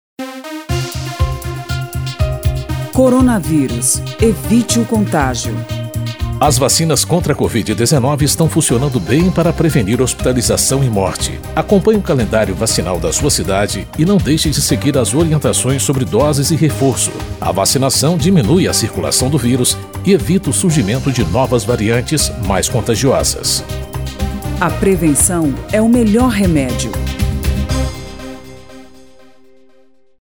spot-vacina-coronavirus-1-1.mp3